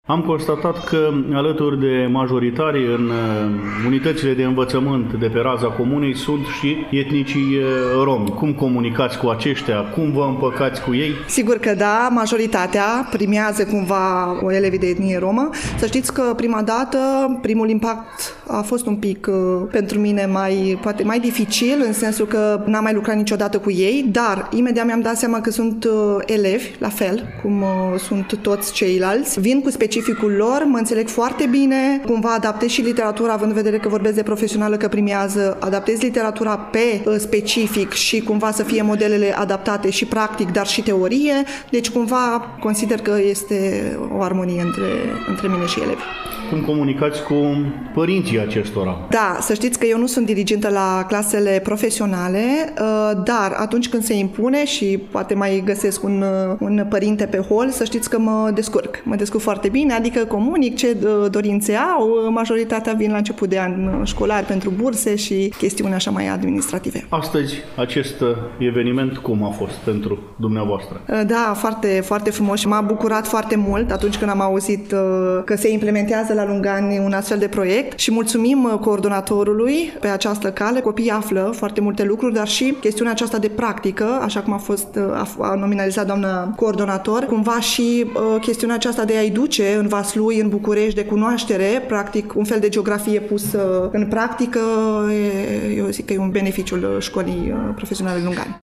Am făcut popas în incinta Școlii Profesionale din Lungani, acolo unde reprezentanții au prezentat tinerilor romi din comună proiectul Suntem activi și împlicați în comunitatea noastră.